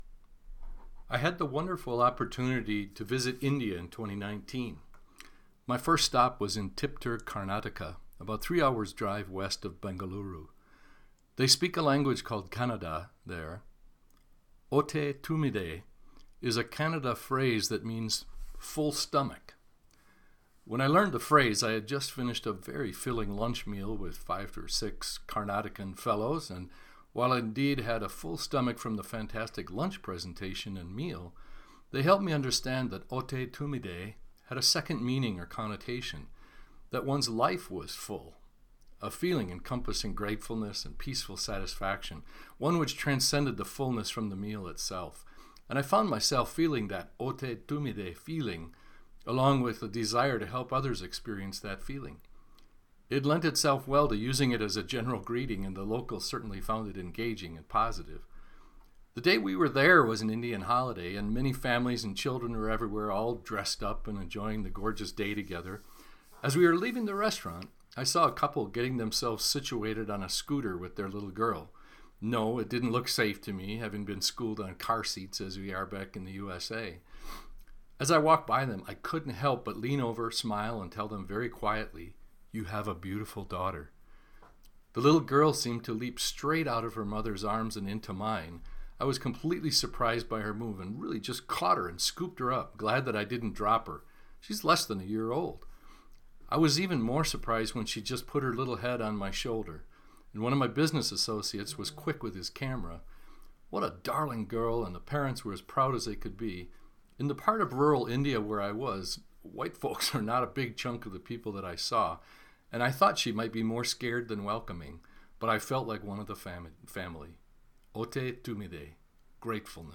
Hoṭṭe tumbide (sounds like “otay toombiday”) is a Kannada phrase that means “Full stomach”.